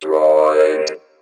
Phone Ringtones